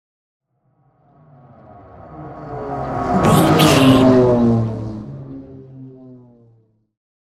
Pass by vehicle engine deep
Sound Effects
pass by
car